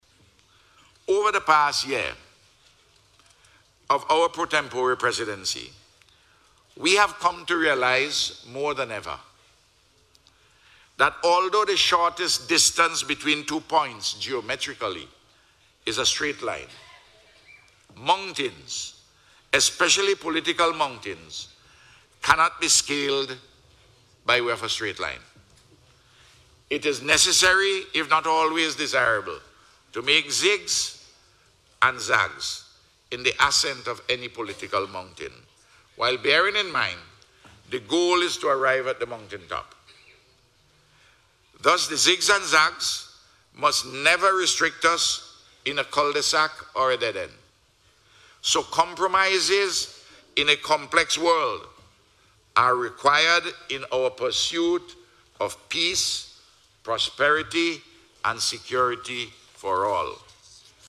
The Prime Minister was delivering an address at the Eighth Summit of the Community of Latin American and Caribbean states this morning at the Sandals Resort at Buccament.